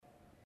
تاريخ النشر ١٦ شوال ١٤٤٠ هـ المكان: المسجد الحرام الشيخ: معالي الشيخ د. سعد بن ناصر الشثري معالي الشيخ د. سعد بن ناصر الشثري كتاب الحروف والقراءات